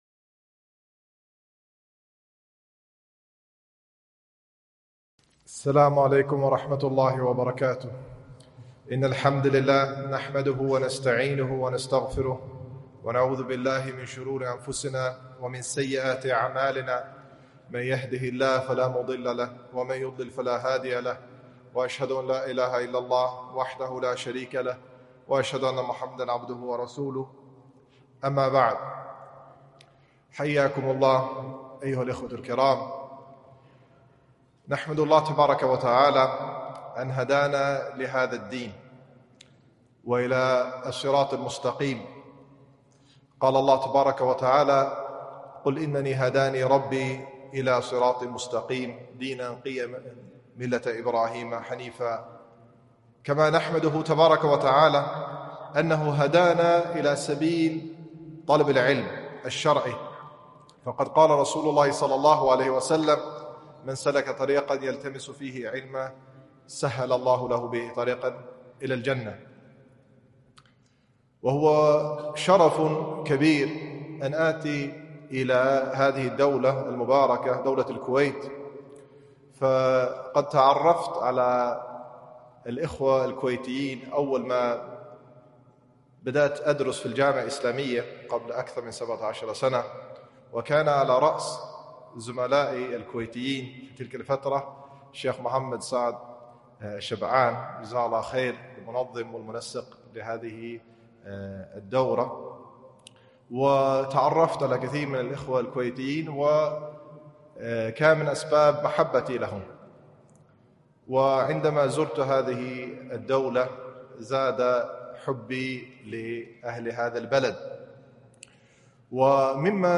محاضرة - أهمية التأصيل العلمي في مواجهة الغزو الفكري